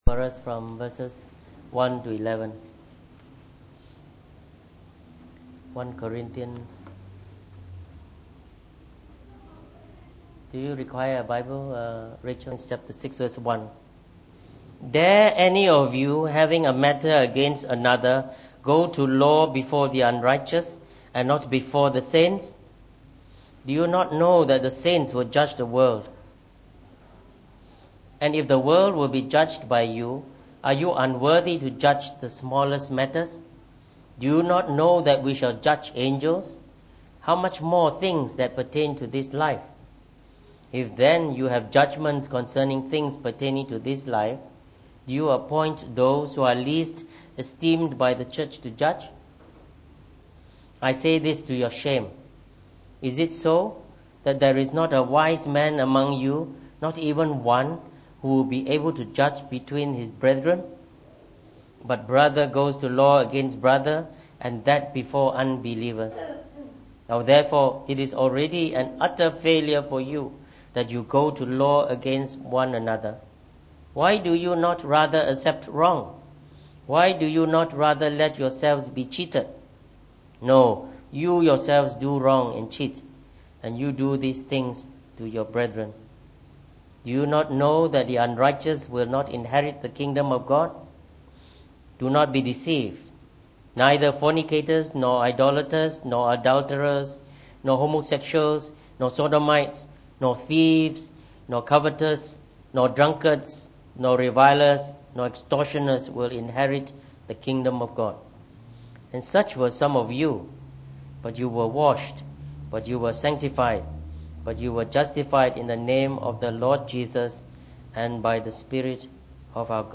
Preached on the 25th of June 2017.